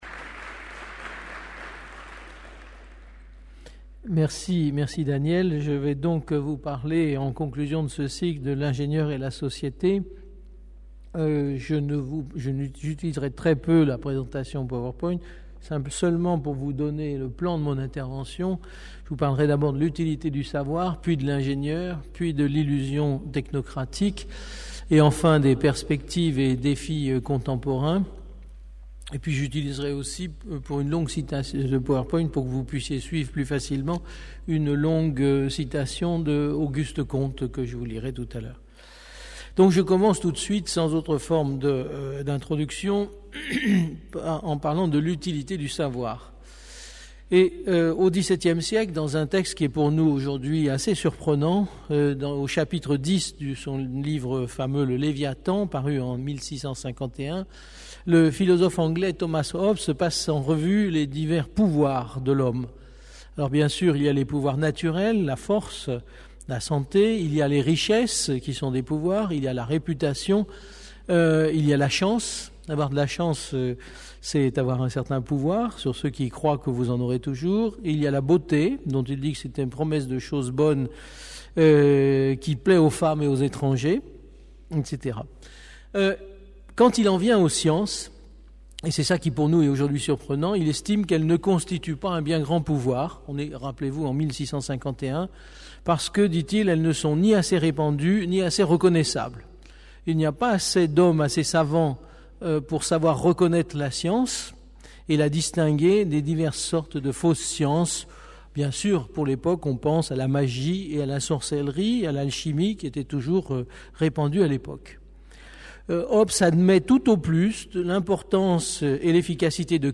Une conférence du cycle : Qu'est-ce qu'un ingénieur aujourd'hui ?